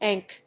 読み方 エヌジーエフ